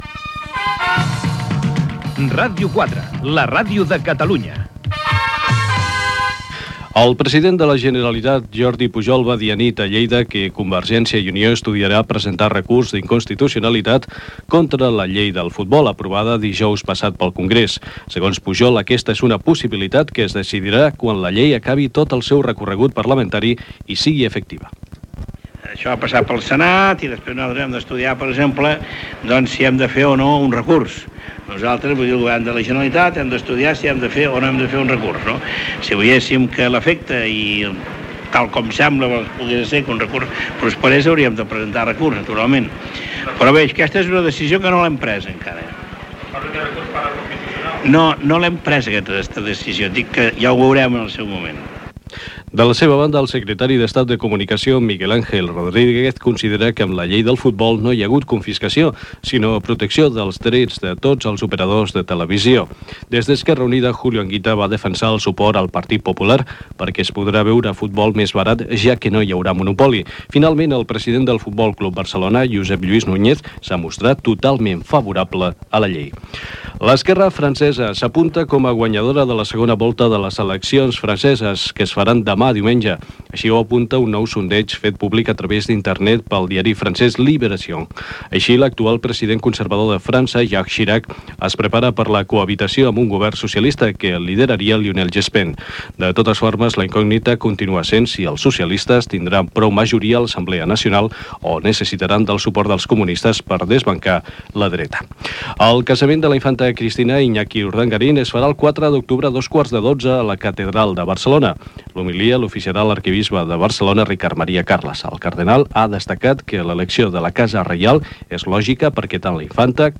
Indicatiu, declaració de Jordi Pujol sobre la Llei del futbol, segona volta de les eleccions franceses, el casament de la infanta Cristina es farà a Barcelona, el temps, indicatiu de l'emissora.
Informatiu